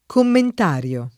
vai all'elenco alfabetico delle voci ingrandisci il carattere 100% rimpicciolisci il carattere stampa invia tramite posta elettronica codividi su Facebook commentario [ komment # r L o ] (antiq. comentario ) s. m.; pl.